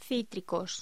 Locución: Cítricos